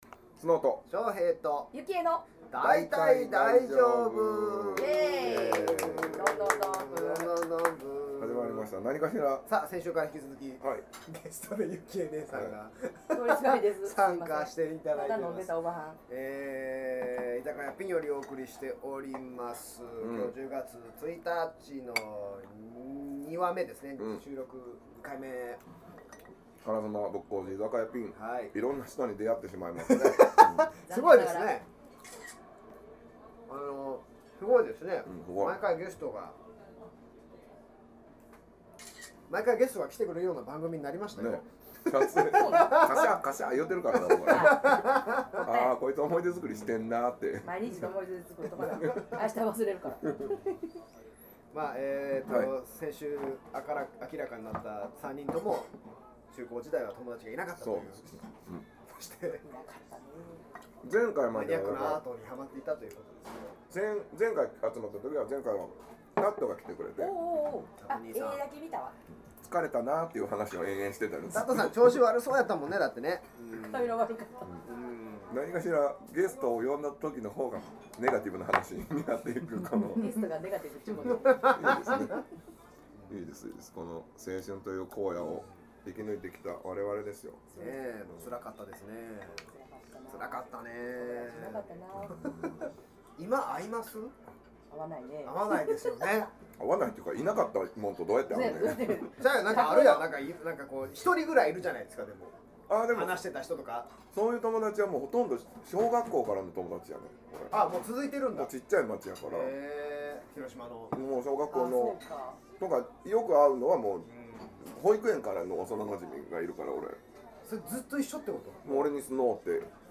（１０月１日収録②）中高時代、友だちがいなかったという共通点がある３人が揃うとこんなにいろいろ話が盛り上がるんですねぇ